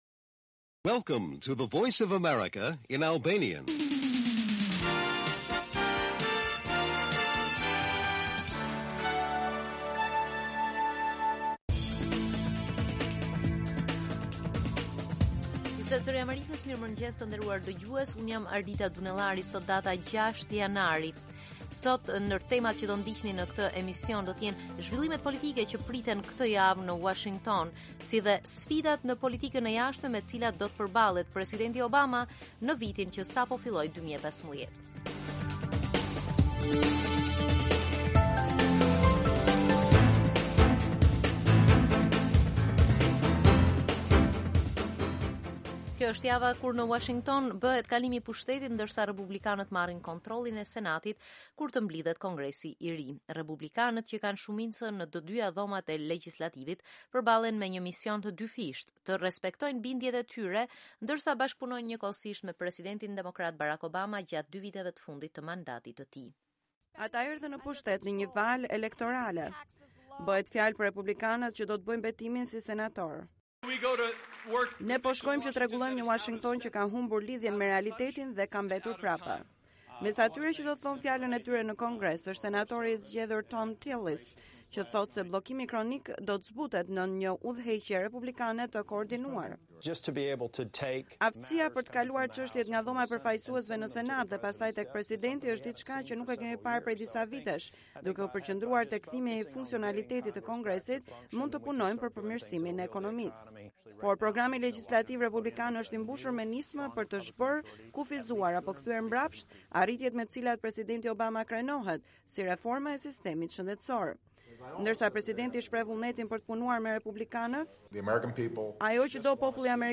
Lajmet e mëngjesit